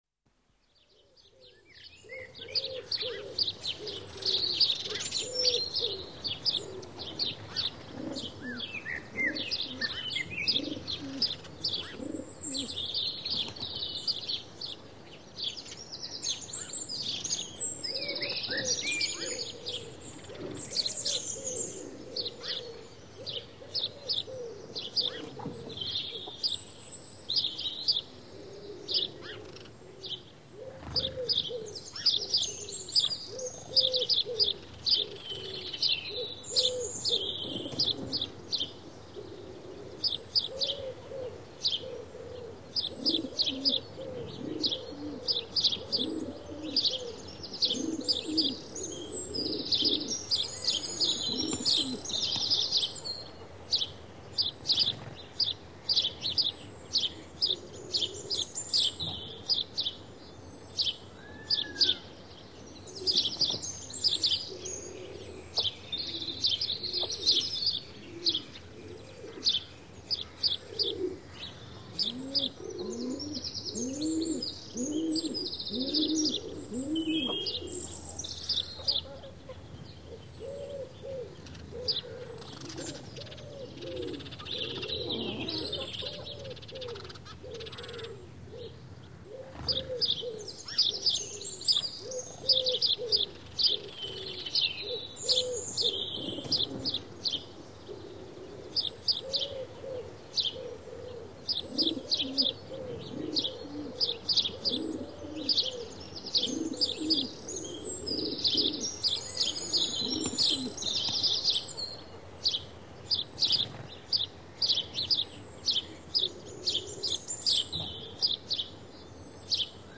Звуки чириканья воробья
Лесные воробьи